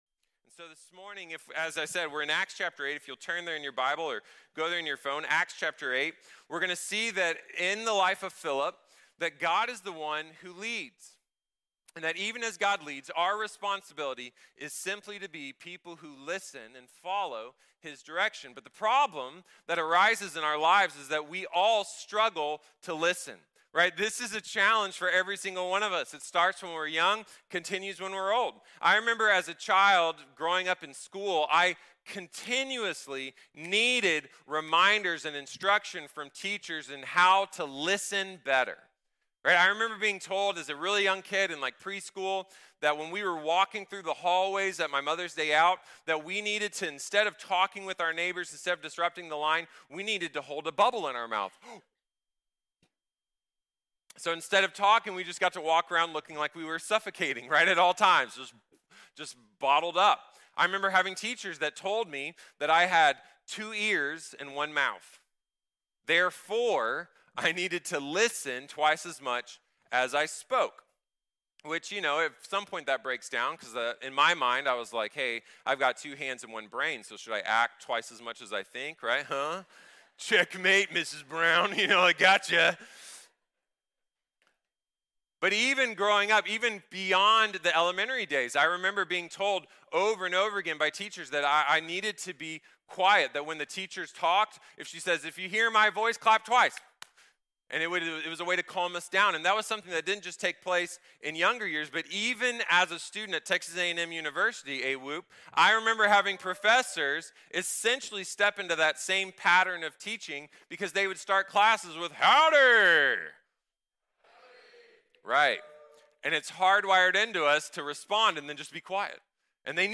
Philip | Sermón | Iglesia Bíblica de la Gracia